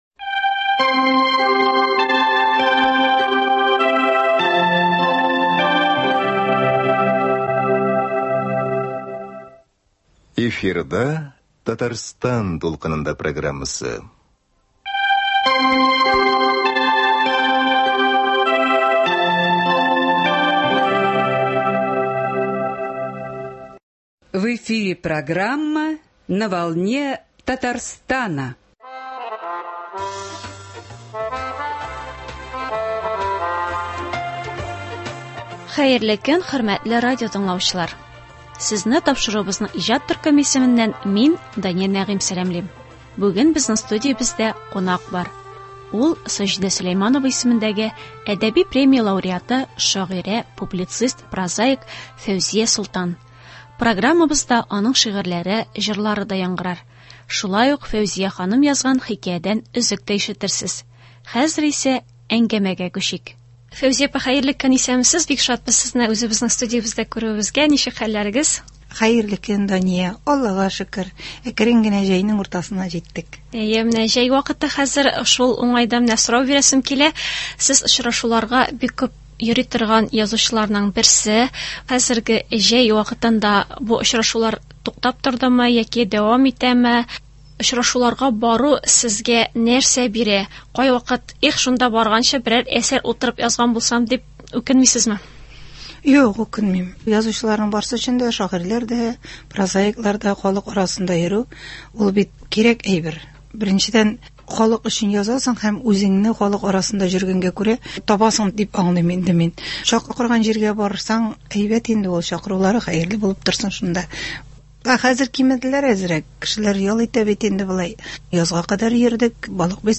Бүген безнең студиябездә кунак бар.
Программабызда аның шигырьләре, җырлары яңгырар.
Хәзер исә әңгәмәгә күчик.